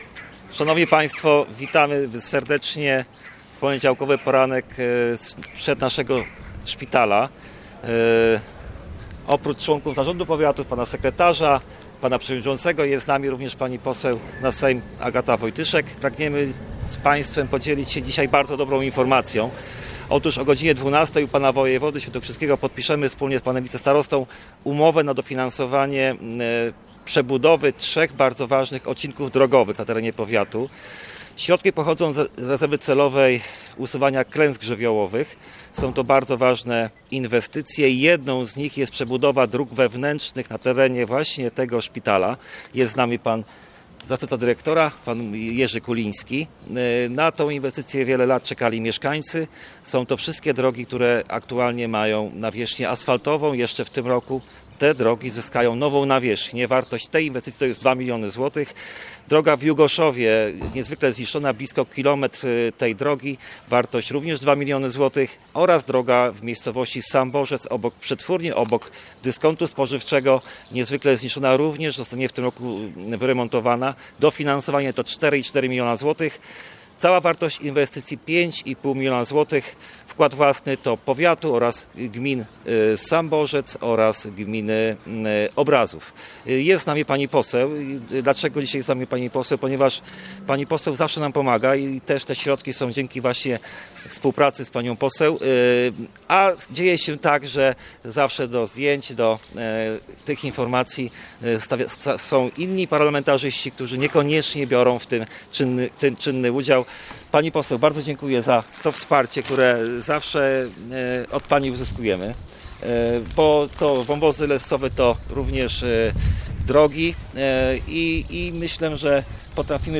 O kolejnych środkach dla powiatu sandomierskiego a także o inwestycjach realizowanych i planowanych w Szpitalu Specjalistycznym Ducha Św. w Sandomierzu mówili dziś uczestnicy konferencji prasowej zorganizowanej pod budynkiem szpitala. Starosta powiatu sandomierskiego Marcin Piwnik oraz wicestarosta Paweł Niedźwiedź dziękowali za wsparcie w pozyskiwaniu pieniędzy na inwestycje poseł Agacie Wojtyszek: